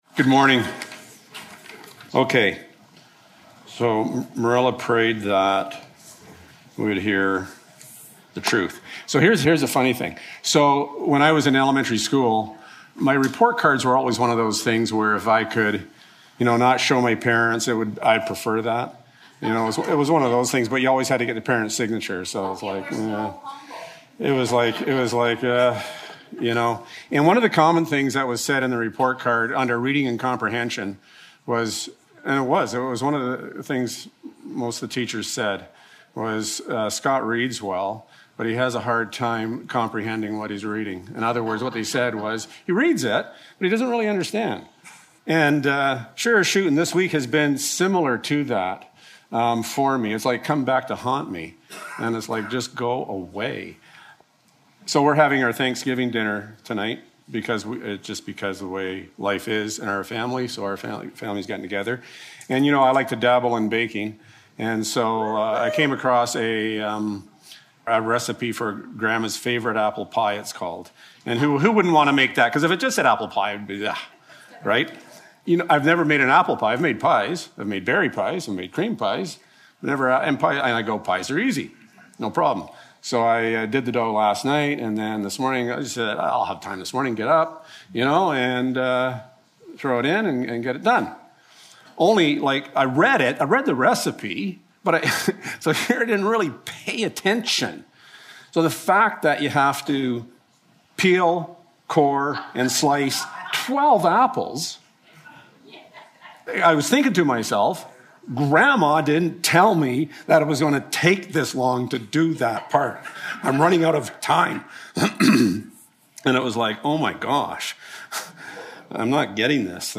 Service Type: Sunday Morning